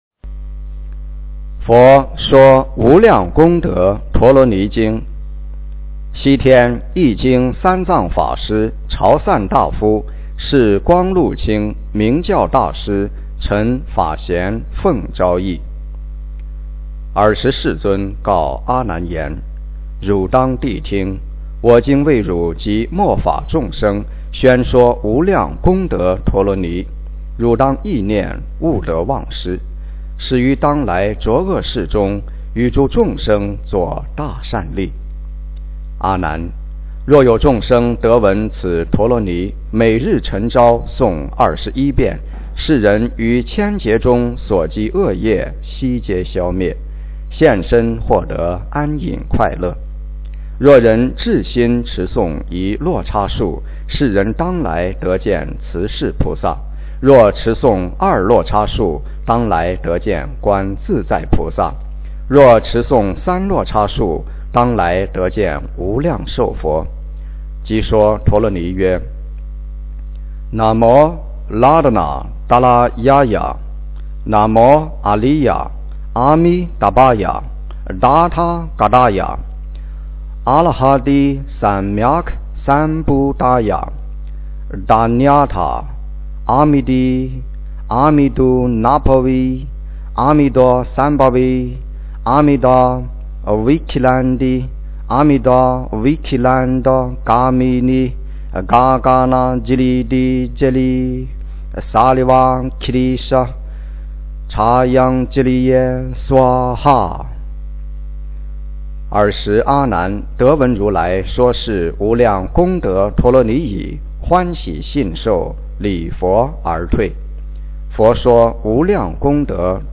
诵经
佛音 诵经 佛教音乐 返回列表 上一篇： 《华严经》62卷 下一篇： 《华严经》67卷 相关文章 無相頌2 無相頌2--佛教音乐...